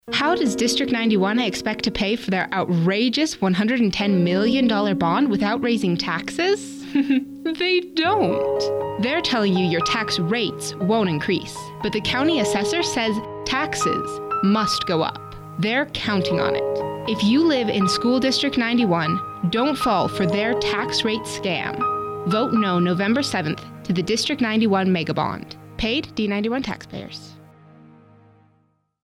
D91-Taxpayers-Radio-2.mp3